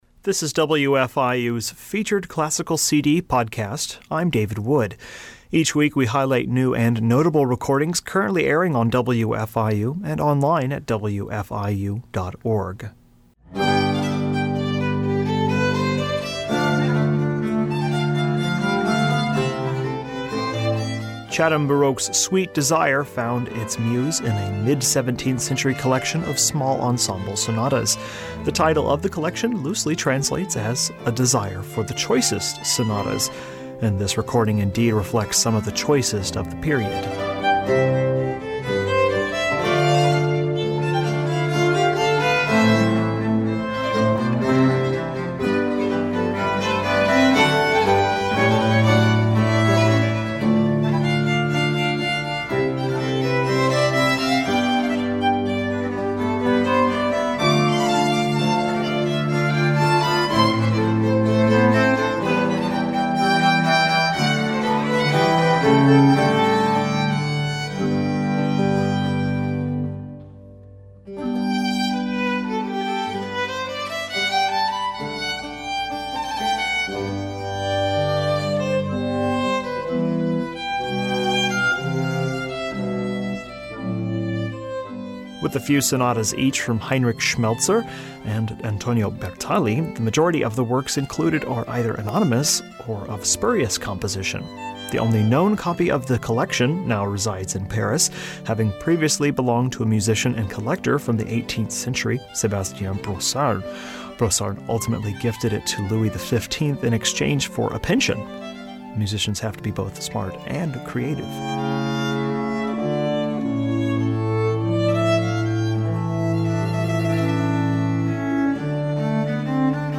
mid-17th century sonatas